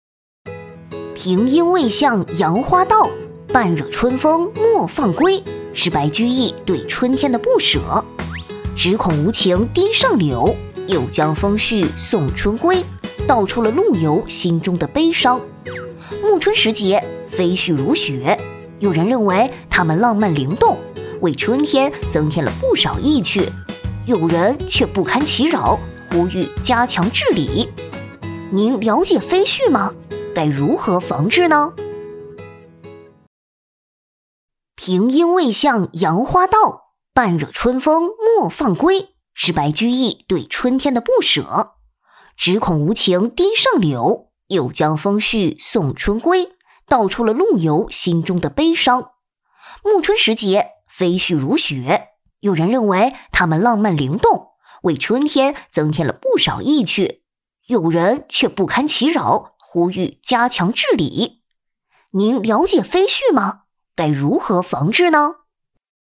年轻温柔配音 电力公司专题知性配音
老师声线宽广，年轻活泼风格、低沉讲述风格都可以录制，可录制英文，价格咨询客服。
女62-MG卡通-让我们一起应对飘洒的飞絮1-1-.mp3